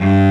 STR XCELLO01.wav